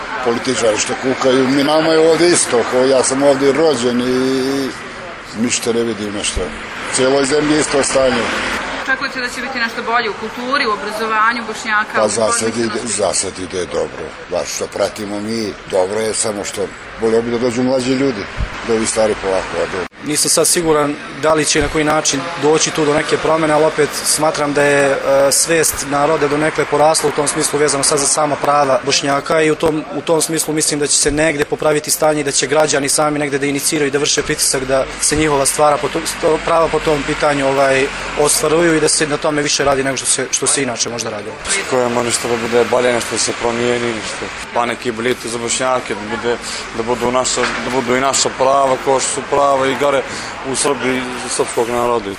Građani na glasačkom mestu u Novom Pazaru